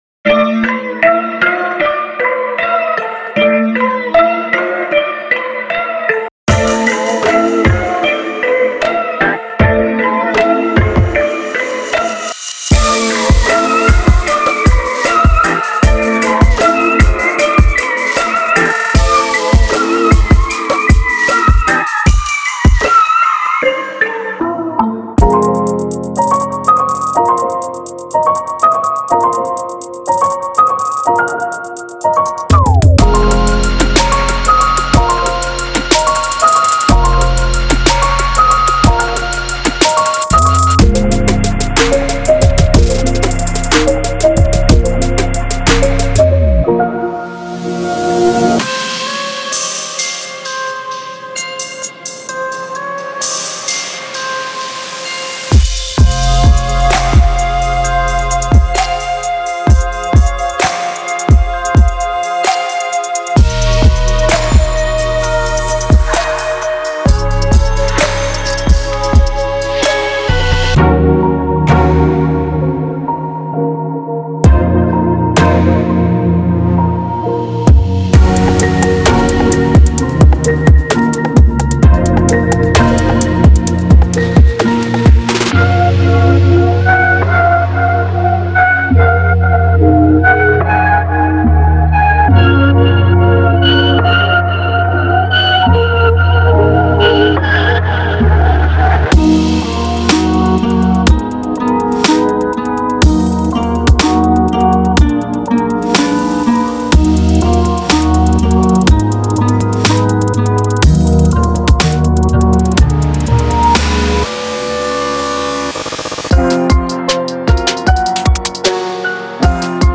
Genre:Trap
なお、このパックはメロディに特化しており、ドラムは含まれていません。
デモサウンドはコチラ↓
64 Synth Loops
62 Keys Loops
19 Strings Loops
7 Guitar Loops